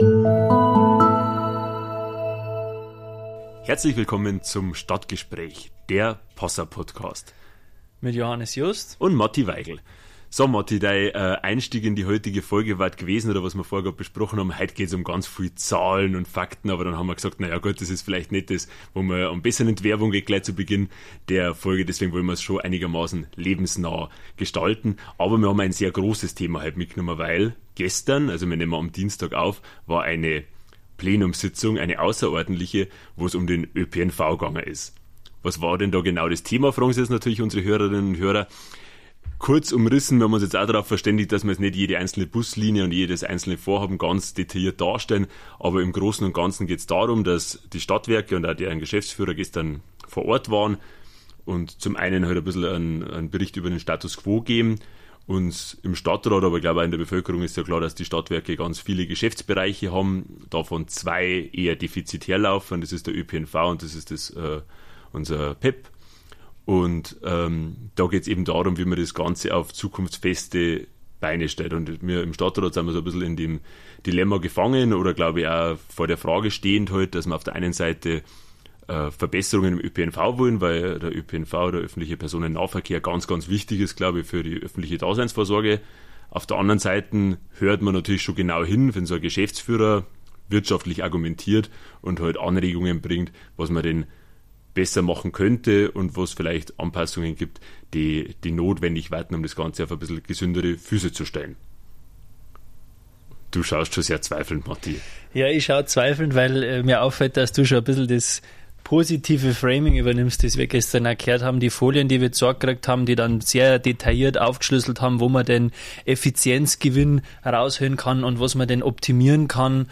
Was wurde in der Sondersitzung des Stadtrats beschlossen – und was blieb offen? Die beiden jüngsten Stadtratsmitglieder, Matthias Weigl (Grüne) und Johannes Just (SPD), sprechen über ihre Eindrücke.